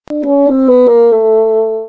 basoon.mp3